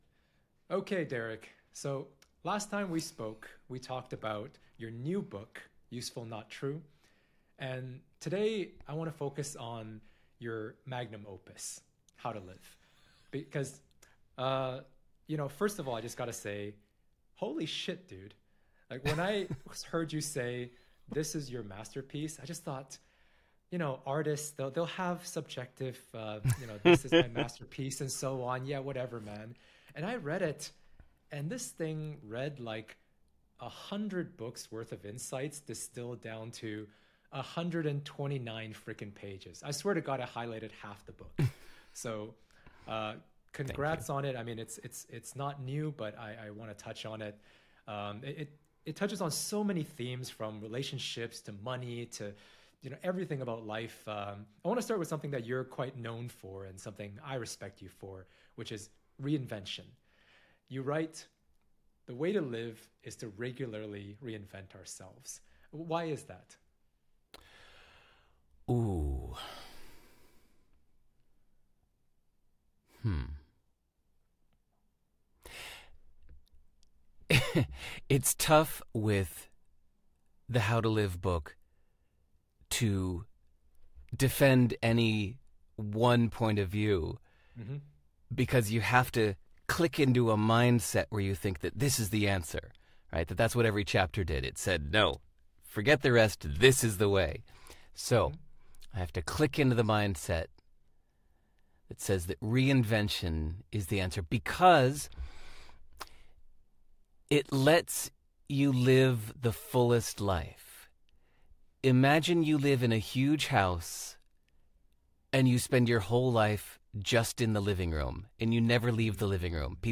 Less Clueless interview